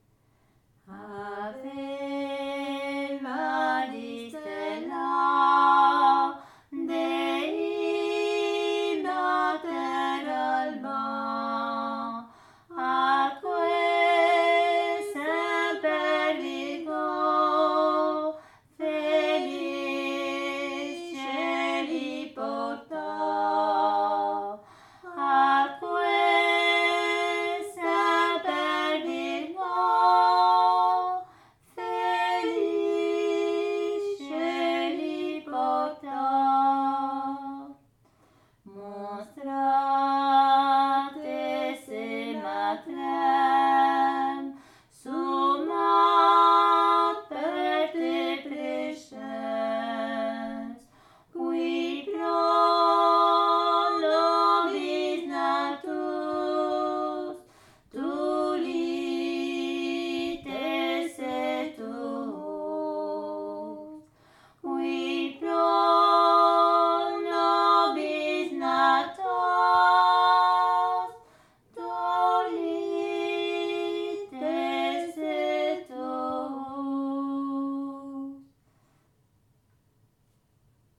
Aire culturelle : Bigorre
Lieu : Ayros-Arbouix
Genre : chant
Effectif : 2
Type de voix : voix de femme
Production du son : chanté
Classification : cantique